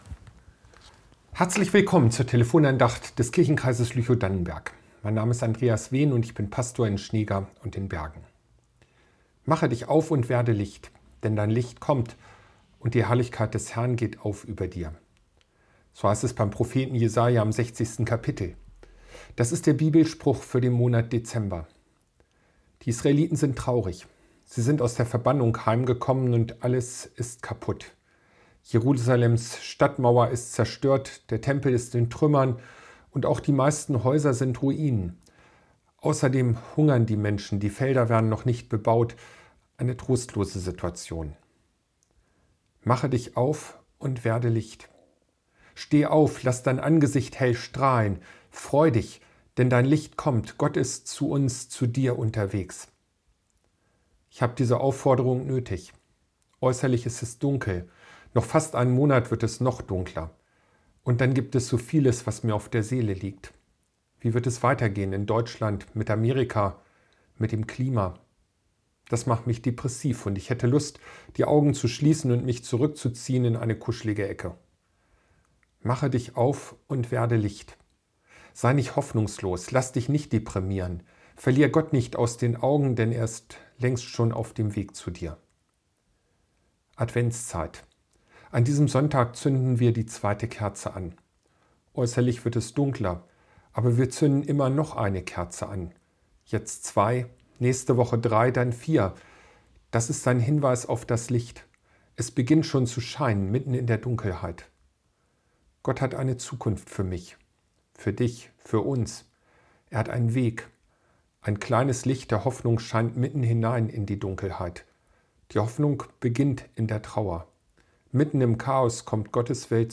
Mach dich auf und werde Licht ~ Telefon-Andachten des ev.-luth. Kirchenkreises Lüchow-Dannenberg Podcast